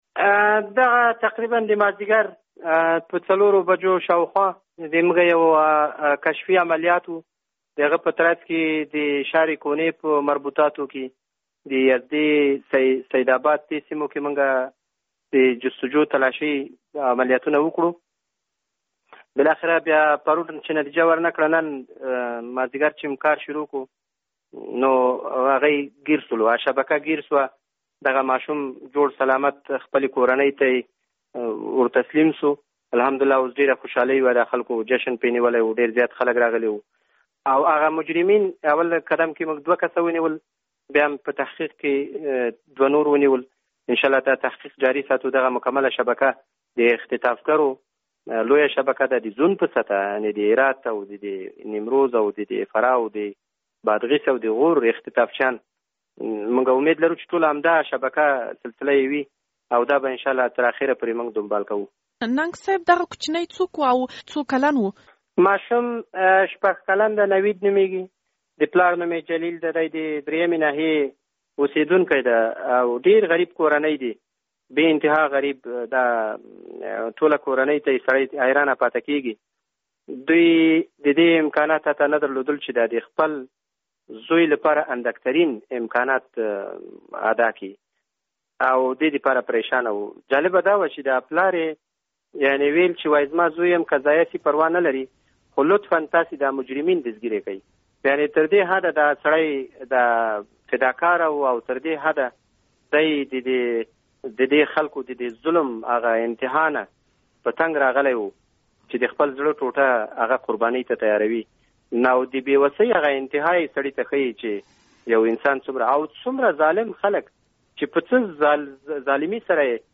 فراه کې د ژغورل شوي ماشوم په اړه له اصف ننګ سره مرکه
مرکه